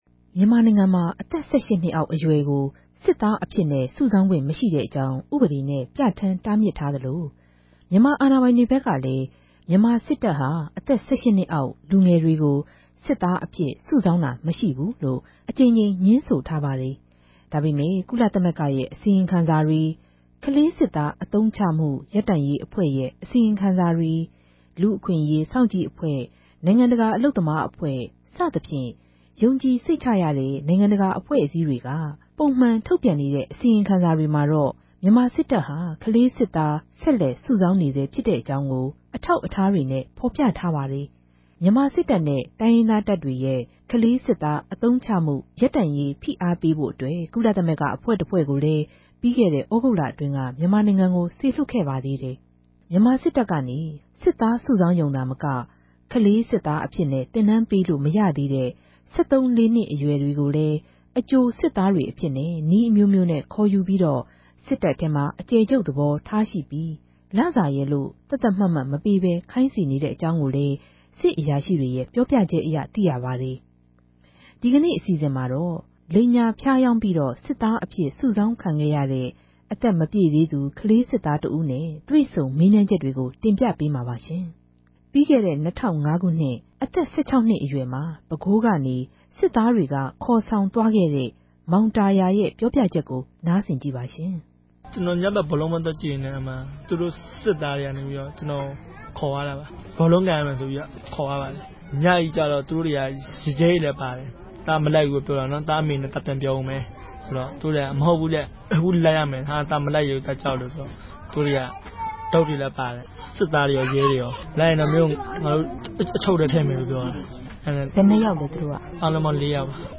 ဒီကလေးစစ်သားဟောင်းကို ထိုင်းိံိုင်ငံတြင်းမြာ ူပီးခဲ့တဲ့လကဘဲ တြေ့ဆုံမေးူမန်းခဲ့တာပၝ။
တြေႚဆုံမေးူမန်းခဵက် (၁)။